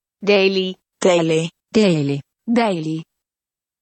File File history File usage Samska_dejlig.ogg (file size: 39 KB, MIME type: application/ogg ) Prono guide for samska DEJLIG File history Click on a date/time to view the file as it appeared at that time.